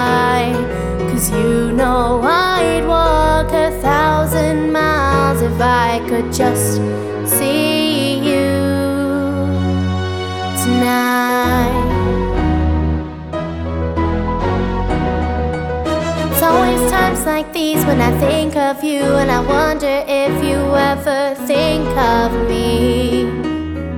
With Lead Vocals